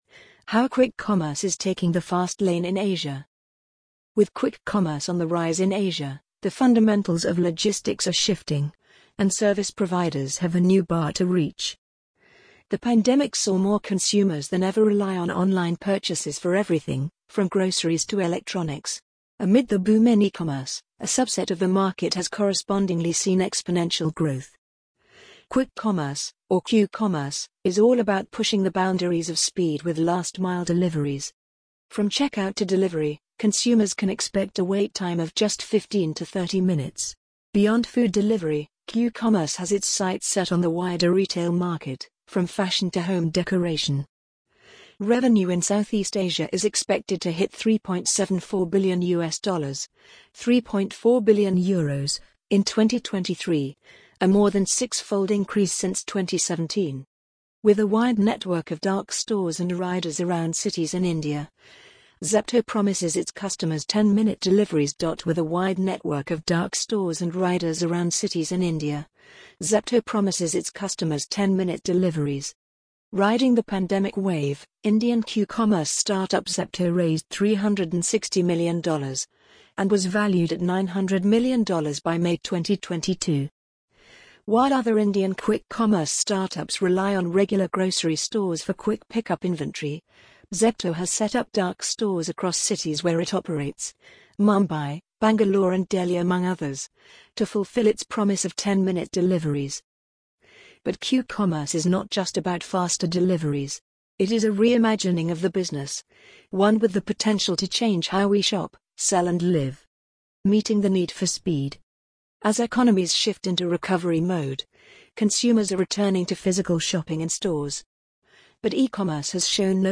amazon_polly_35412.mp3